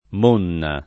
m1nna] s. f. (scherz. ant. «scimmia; sbornia») — es.: Tutti cotti come monne [t2tti k0tti k1me mm1nne] (Redi) — pn. chiusa dichiarata dal Redi e confermata nel ’700 da altri — region. ant. mona [m1na]; da cui prob., per traslato, l’altra voce mona spreg. e triviale